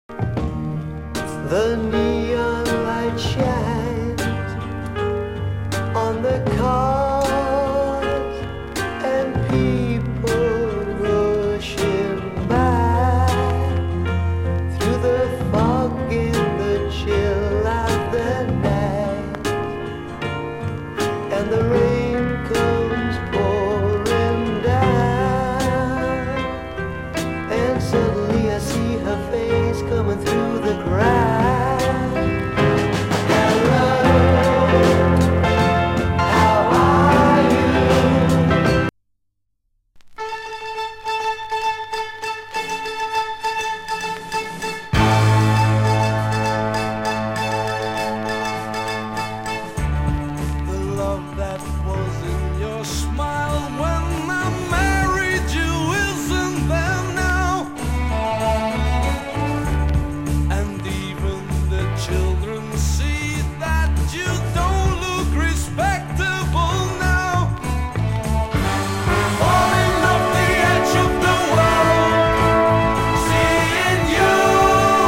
先のヒット曲のイメージを覆すような、恐ろしいほど美しいバラードに思わず感涙。